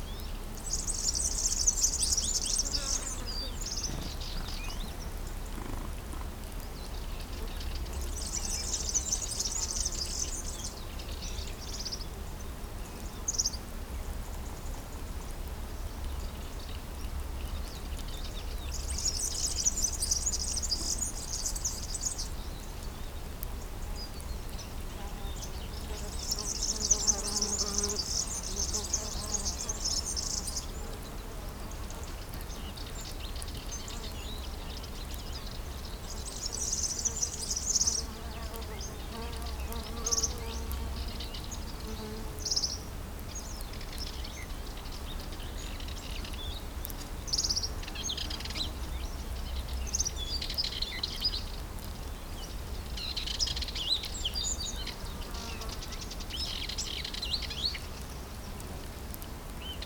day_countryside_2.ogg